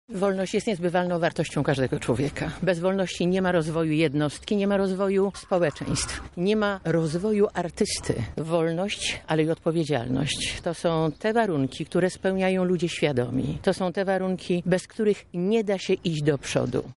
Konferencja PO